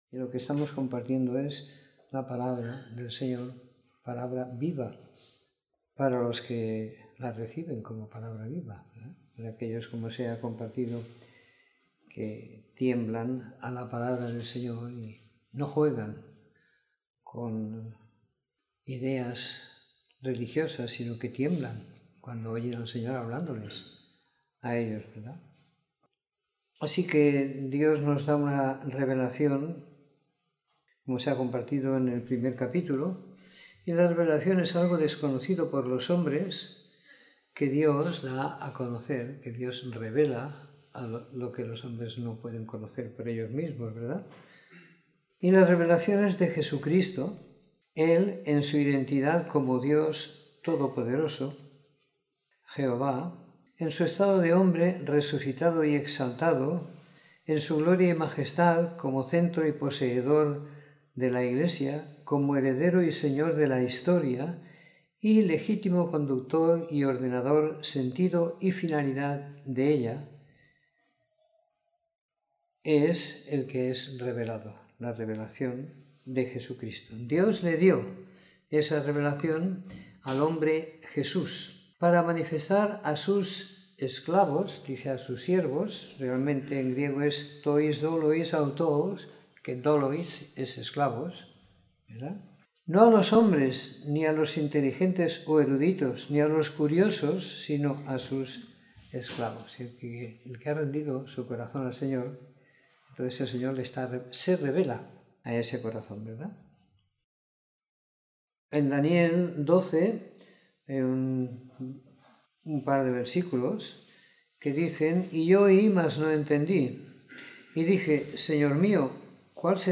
Comentario en Apocalipsis 1-22 / Salmo 45 / Isaías 11; 35; 40; 59; 66 / Hechos 1